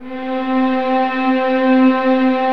VIOLINS DN-R.wav